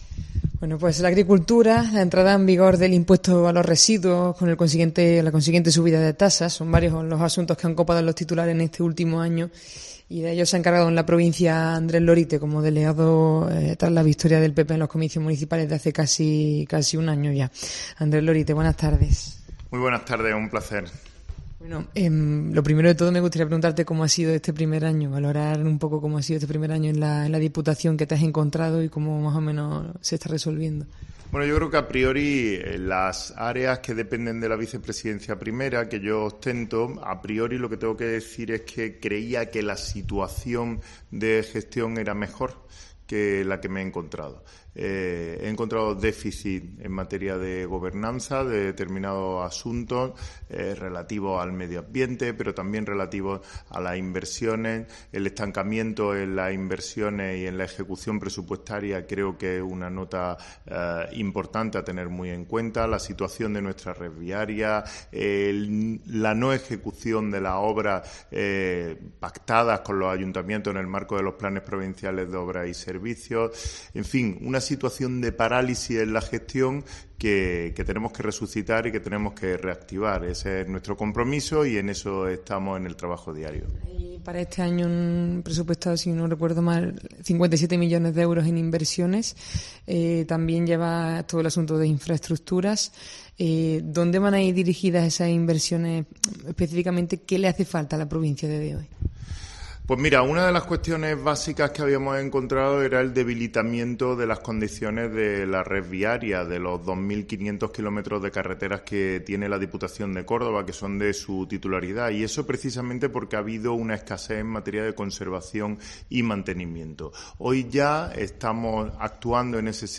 Escucha la entrevista a Andrés Lorite, vicepresidente de la Diputación